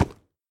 1.21.5 / assets / minecraft / sounds / mob / horse / wood4.ogg
wood4.ogg